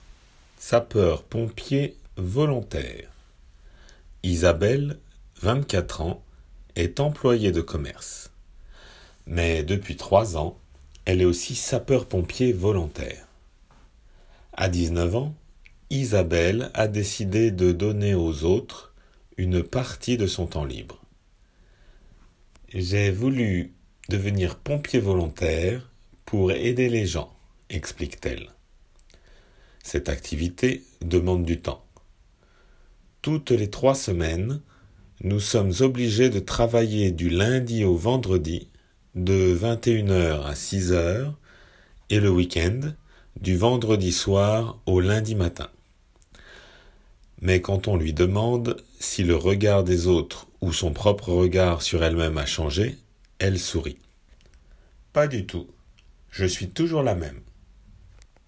普通の速さで